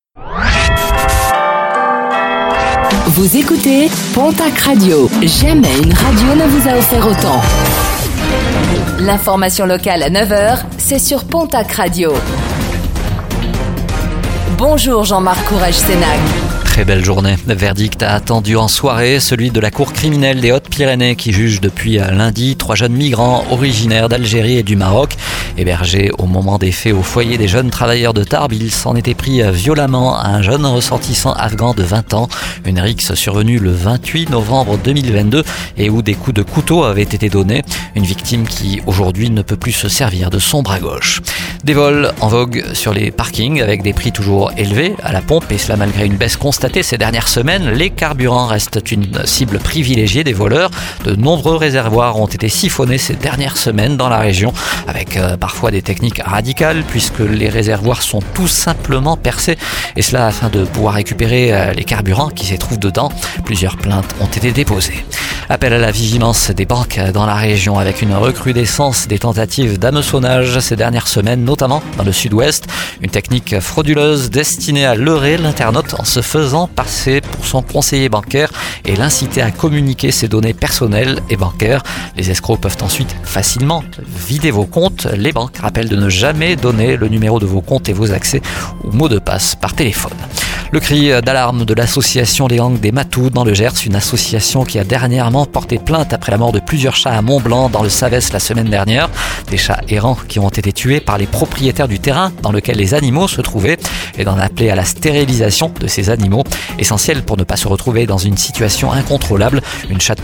Réécoutez le flash d'information locale de ce mercredi 06 novembre 2024